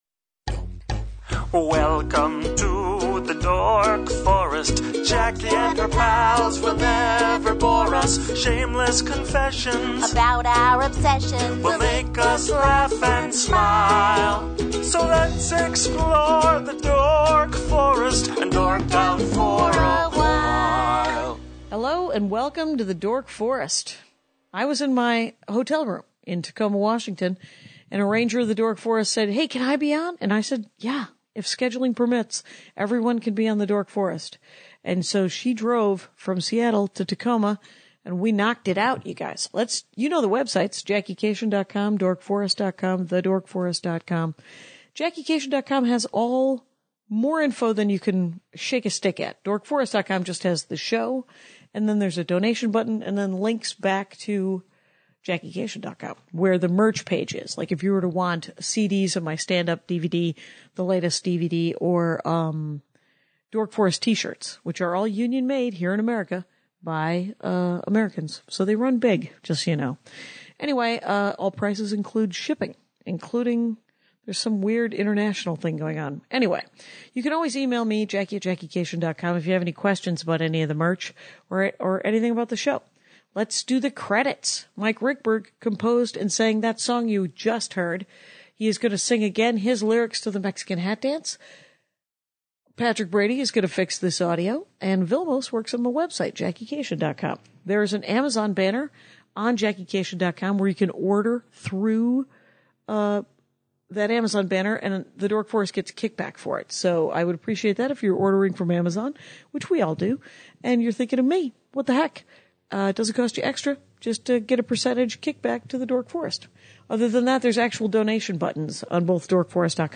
Both of us describe her photos.